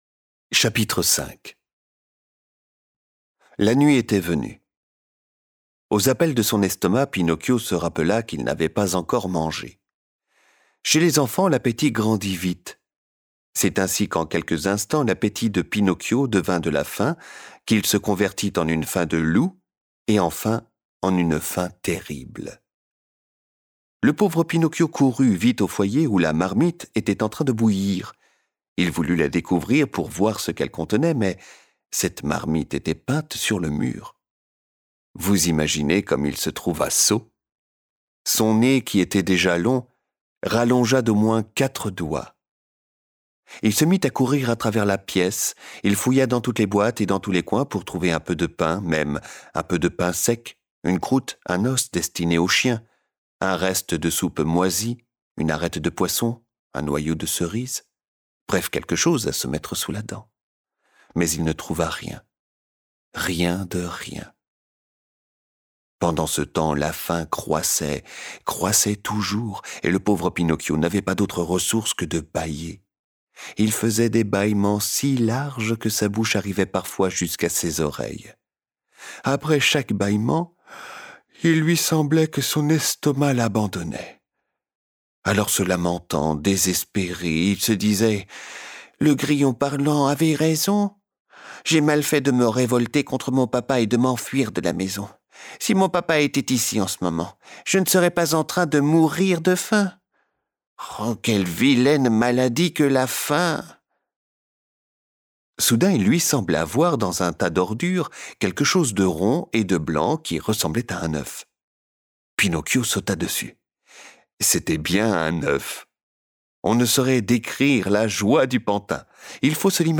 Envie de découvrir de la littérature audio en famille ?